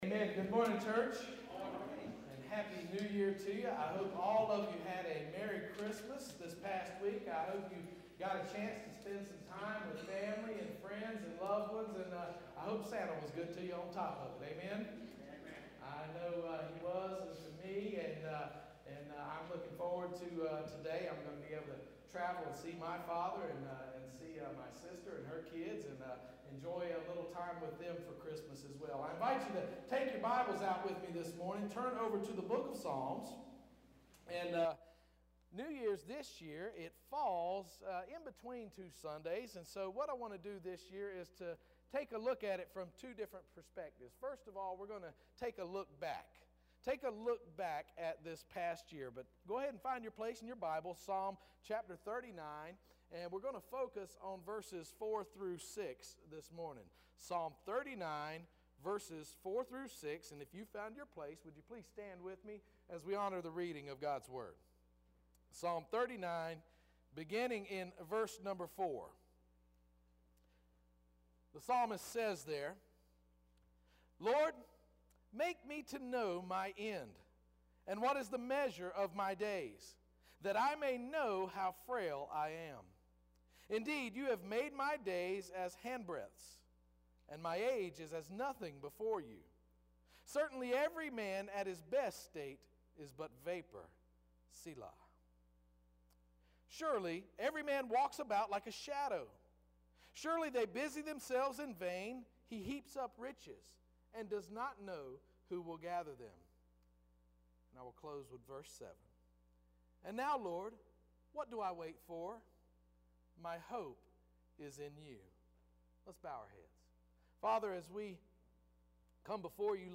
First Baptist Church Roanoke Rapids Online Sermons